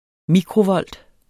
Udtale [ ˈmikʁoˌvʌlˀd ]